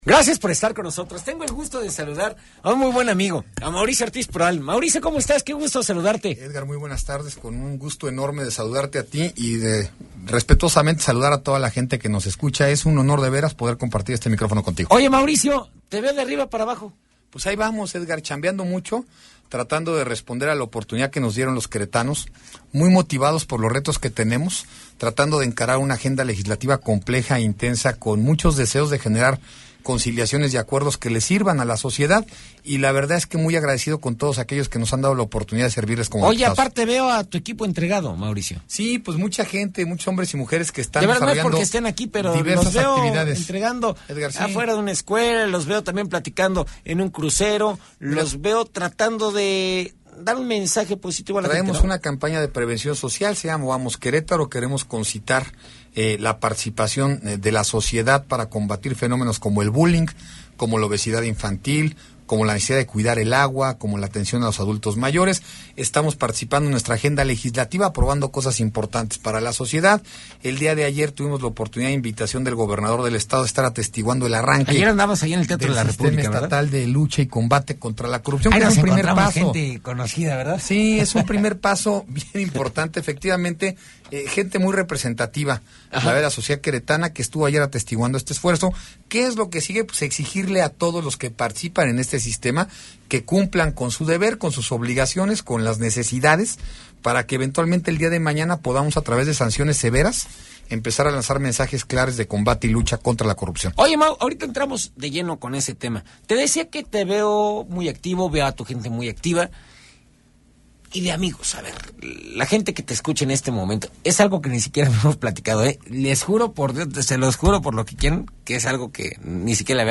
Entrevista con el coordinador de diputados del PRI en el Congreso Local, Mauricio Ortiz Proal - RR Noticias